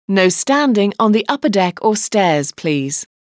nostanding.mp3